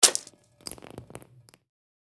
Download Free Gore Sound Effects | Gfx Sounds
Blood-or-gore-splatter-fabric-surface.mp3